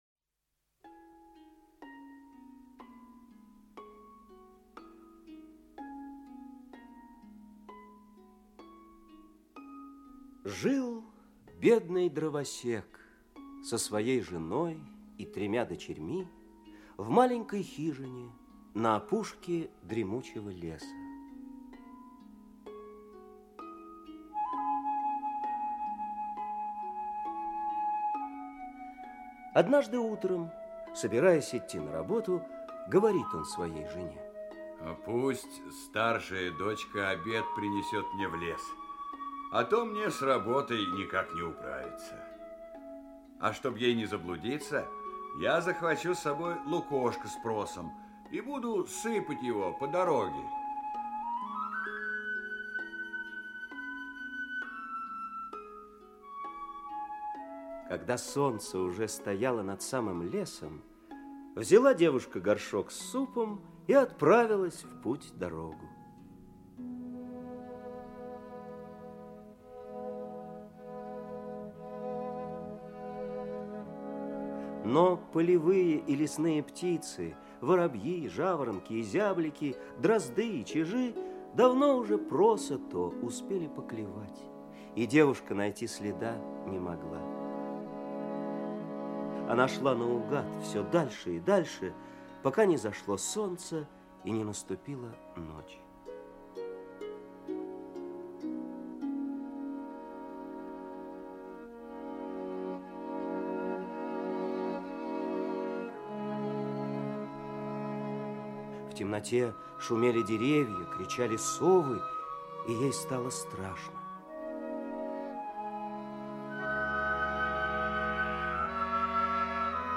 Лесная избушка - аудиосказка Братьев Гримм - слушать онлайн